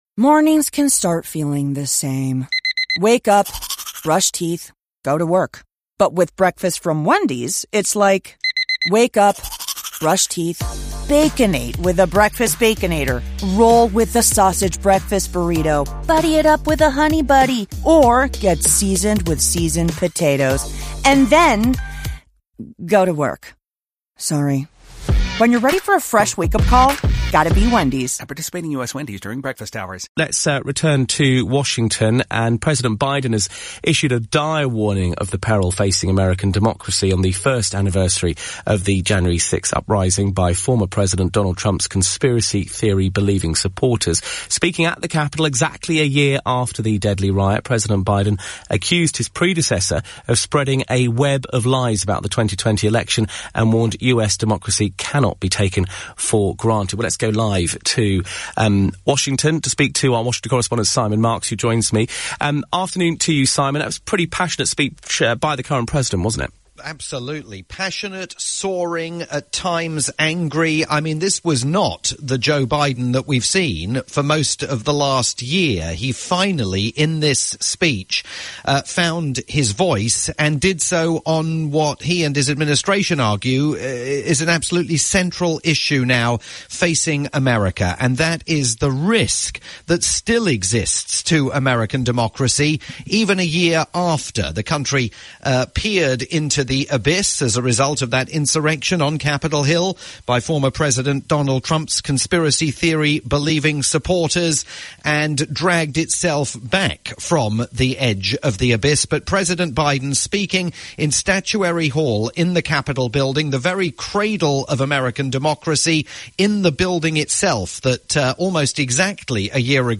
live update for LBC News in the UK